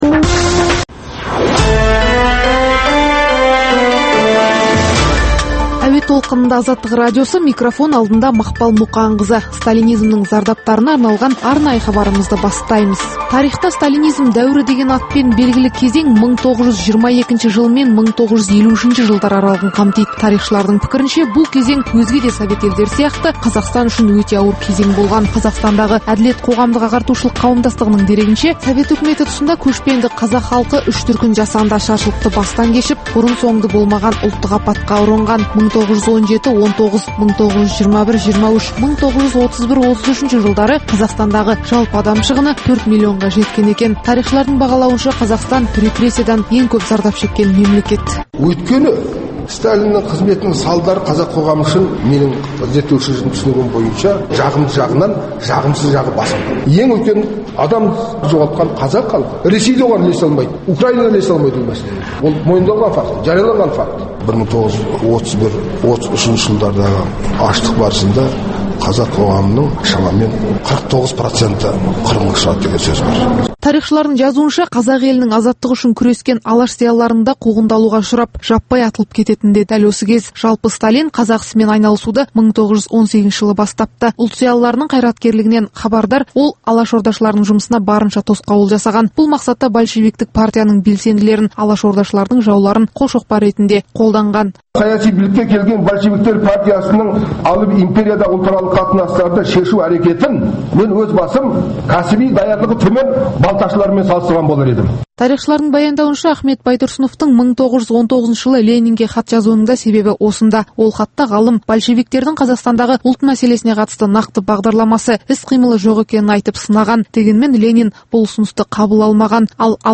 Өткен тарихты зерделеу, ақтаңдақтар мен ұлт тарихындағы қиын-қыстау күндердің бүгінгі тарихта бағалануы тұрғысында тарихшы – зерттеушілермен өткізілетін сұхбат, талдау хабарлар.